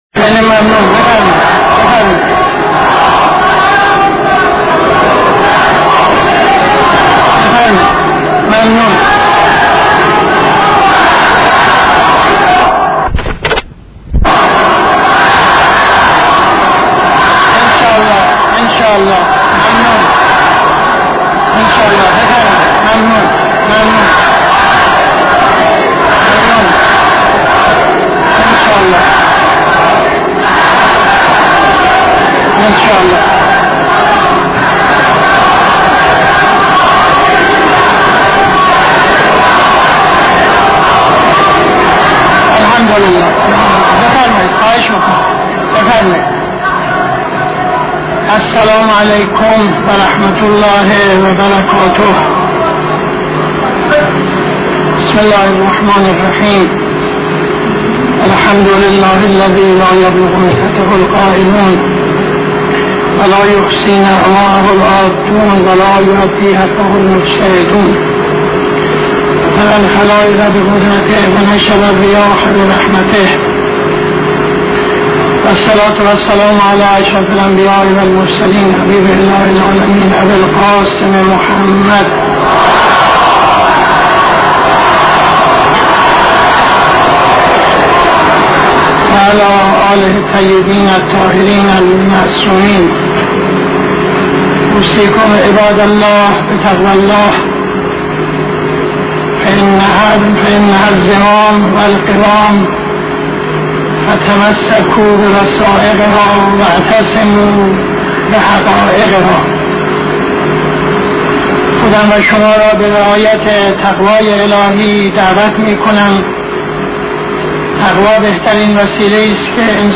خطبه اول نماز جمعه 01-02-74